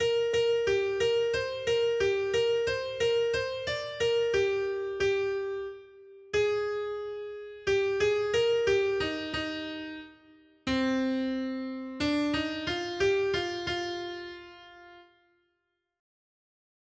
Noty Štítky, zpěvníky ol174.pdf responsoriální žalm Žaltář (Olejník) 174 Skrýt akordy R: Bože, ve své dobrotě ses postaral o chudáka. 1.